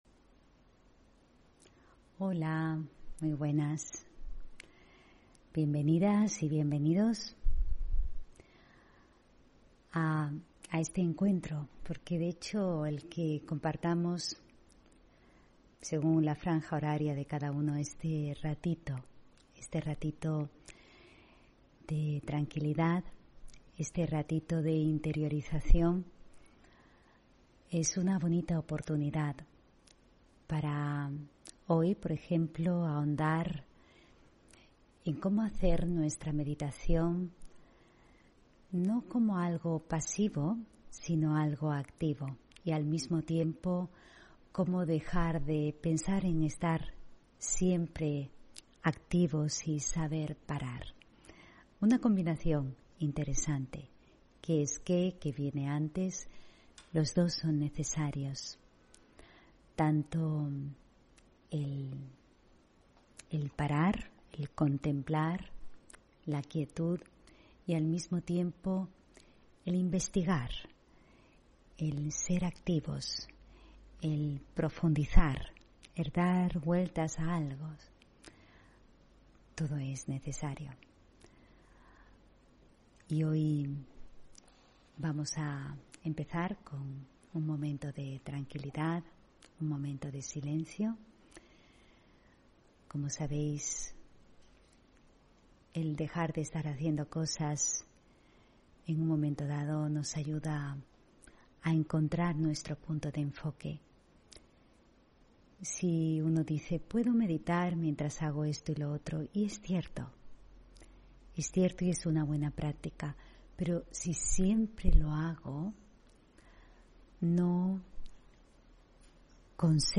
Meditación y conferencia: Meditar, ¿una experiencia activa o pasiva?